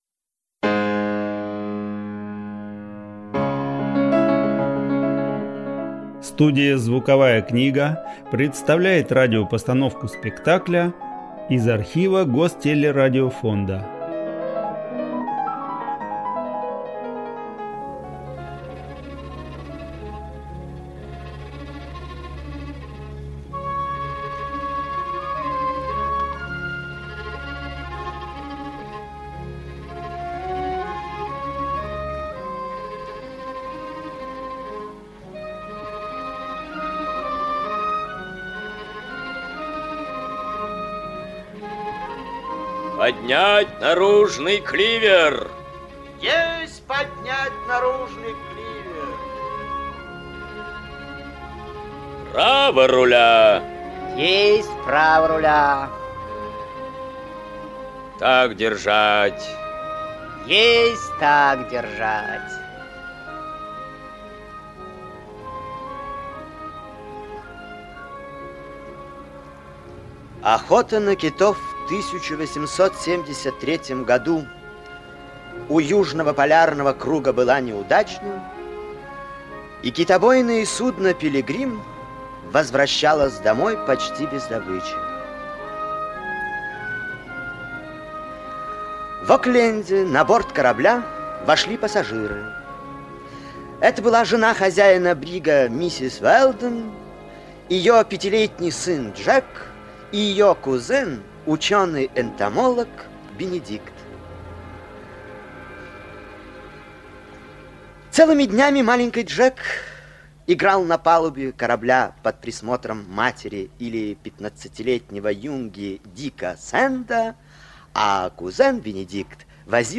Аудиокнига Пятнадцатилетний капитан (спектакль) | Библиотека аудиокниг
Aудиокнига Пятнадцатилетний капитан (спектакль) Автор Жюль Верн Читает аудиокнигу Ольга Пыжова.